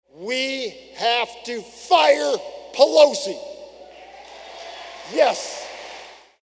That’s 4th District Congressman Randy Feenstra, of Hull, addressing delegates at the Iowa Republican Party’s state convention on Saturday.